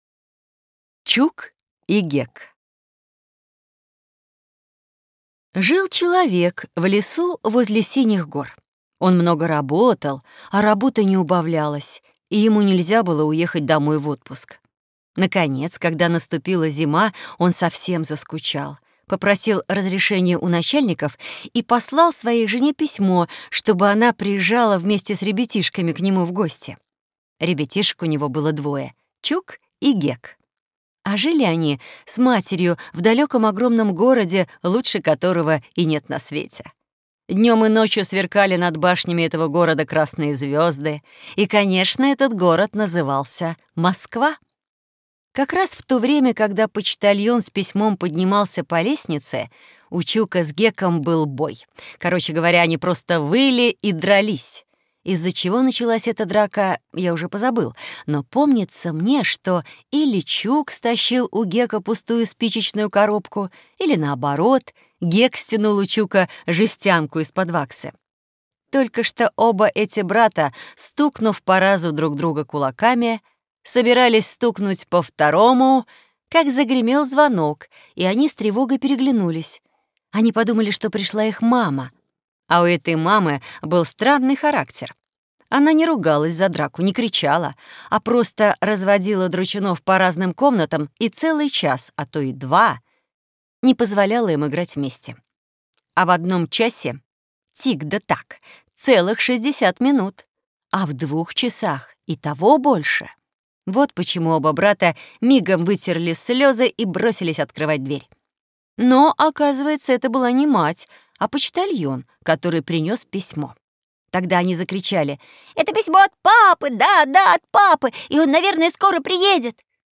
Аудиокнига Чук и Гек | Библиотека аудиокниг